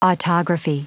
Prononciation du mot autography en anglais (fichier audio)
Prononciation du mot : autography